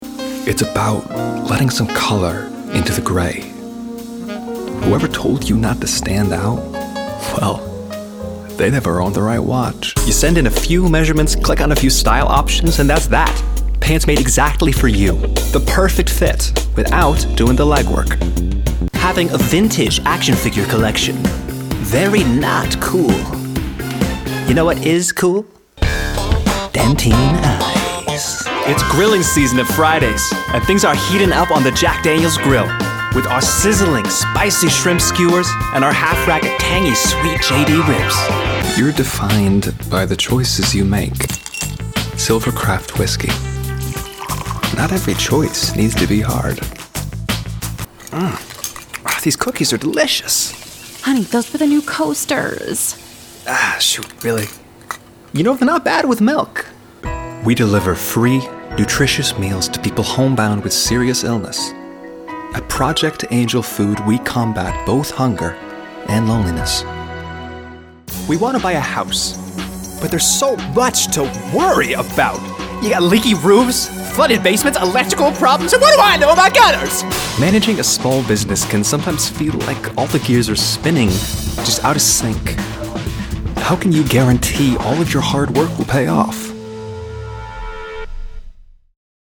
Commercial Demo